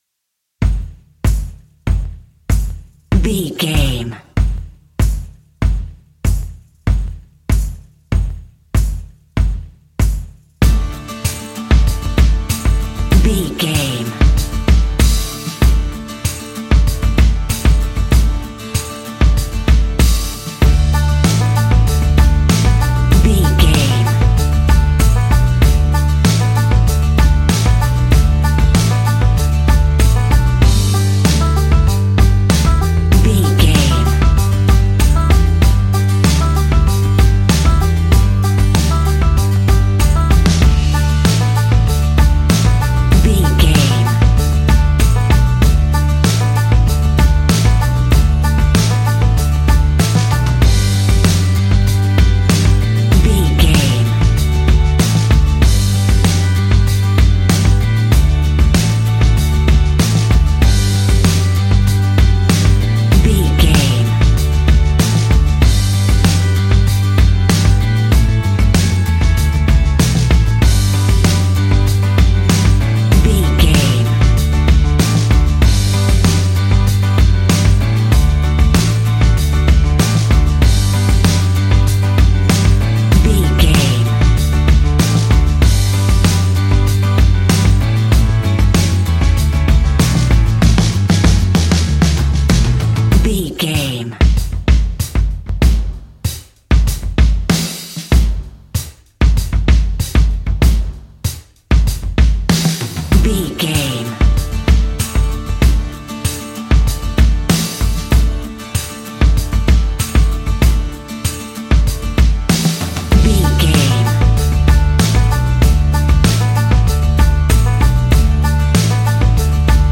Uplifting
Ionian/Major
D
acoustic guitar
mandolin
ukulele
lapsteel
drums
double bass
accordion